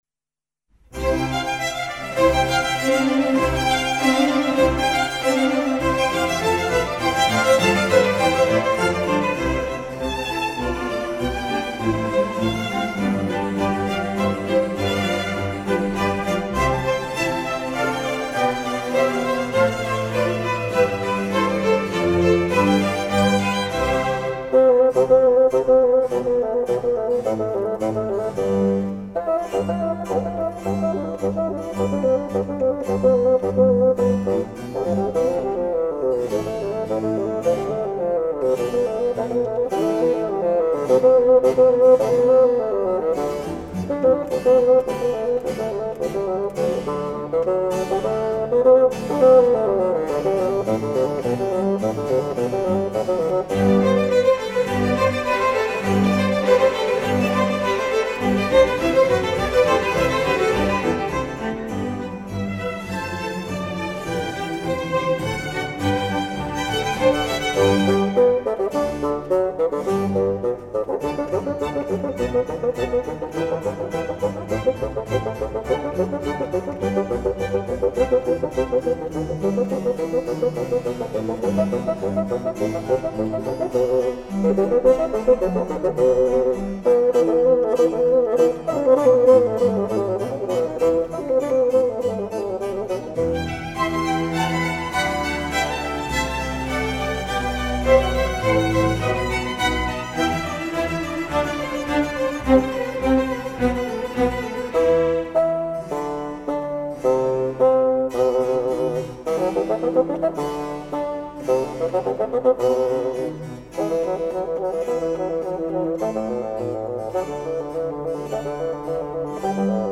Concertos for Strings
Allegro - Largo - Allegro